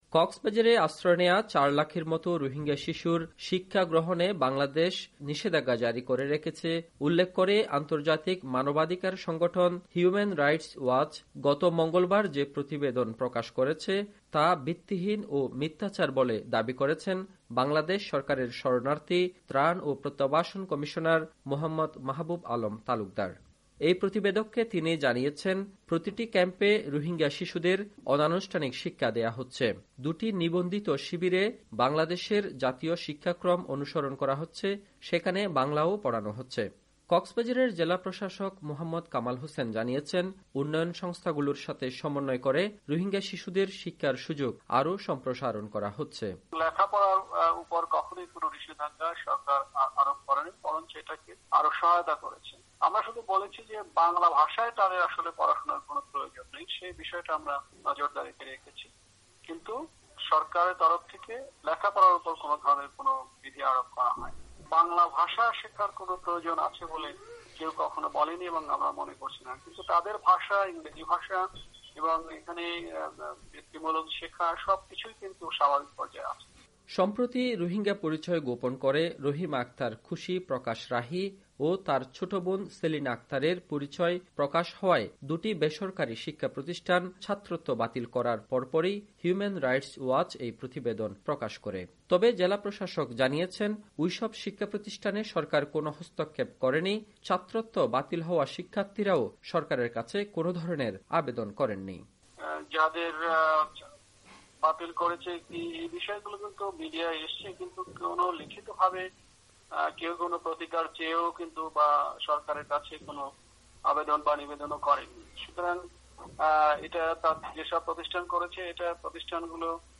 কক্সবাজার থেকে
রিপোর্ট।